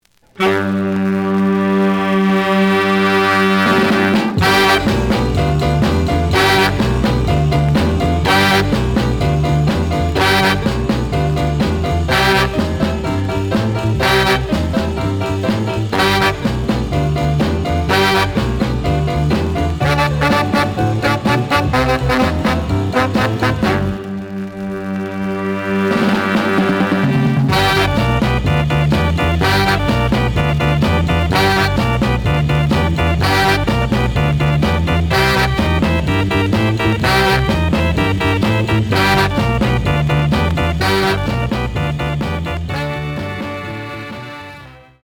The audio sample is recorded from the actual item.
●Genre: Soul, 60's Soul
Some noise on A side.